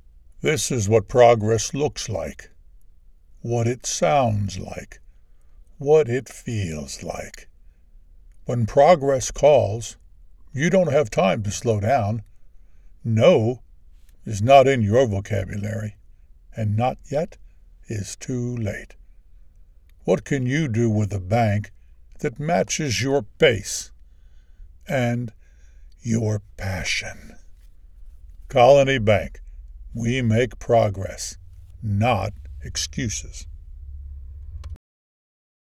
VO Samples
Senior